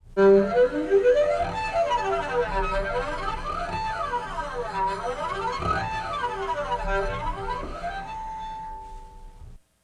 Source: Harmonic gliss on G (15:12-16:00)
Processing: Granulated, 10 voices, plus KS = 305, F = 1010, 21:1 stretch
Harm_Gliss_G.aiff